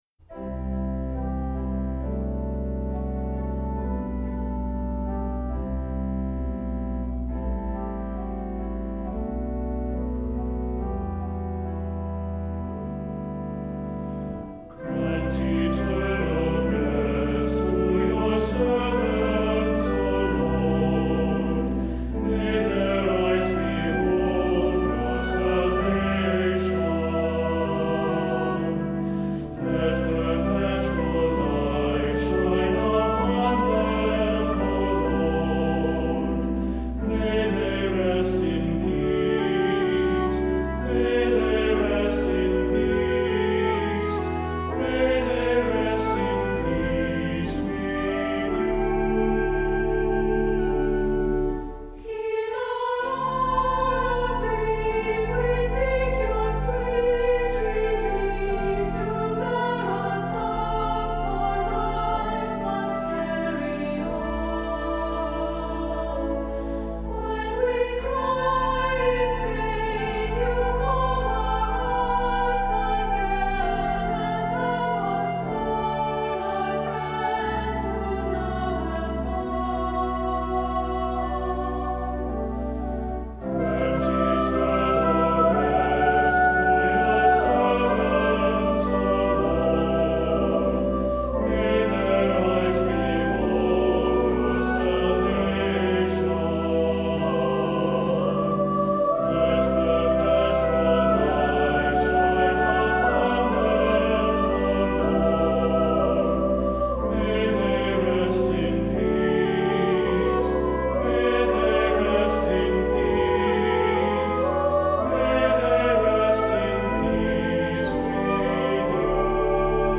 Voicing: Unison with descant; Cantor; Assembly